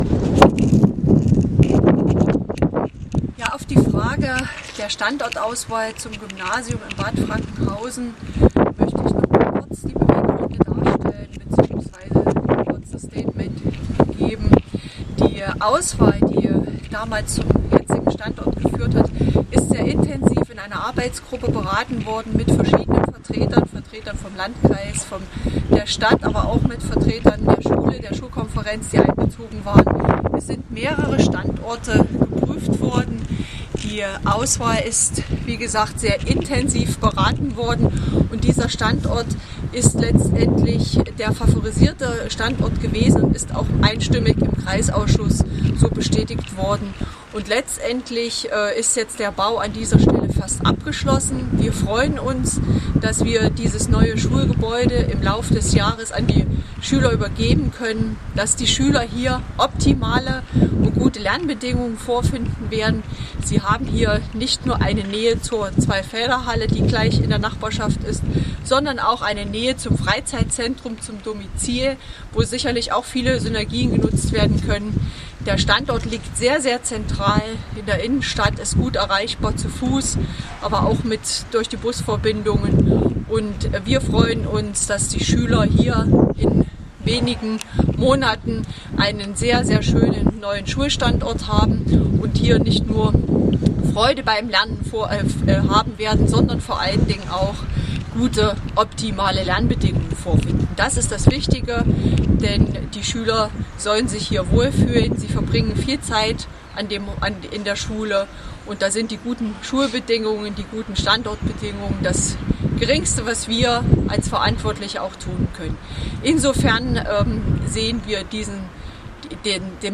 Im Rande einer Veranstaltung in der Zweifelder-Halle Bad Frankenhausen hatte kn die Möglichkeit Landrätin Antje Hochwind-Schneider (SPD) zur Problematik Standort eine Stellungnahme abzugeben.
Stellungnahme Landrätin